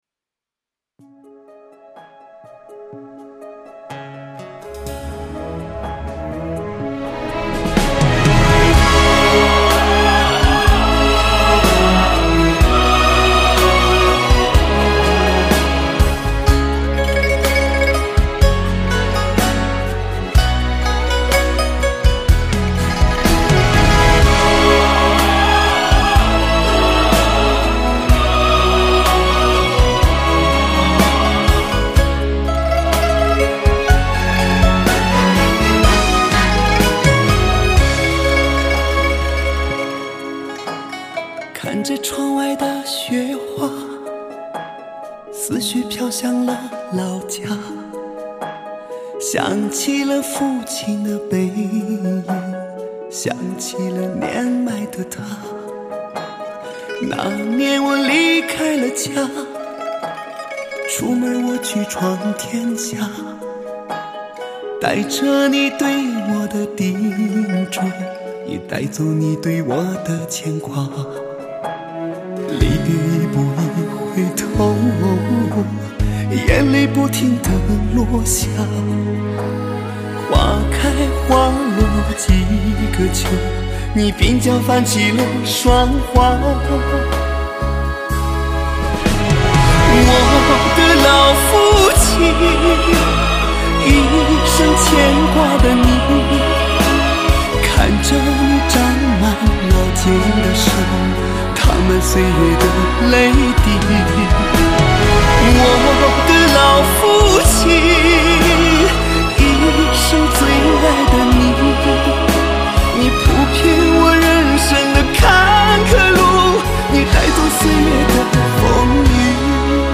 风格：流行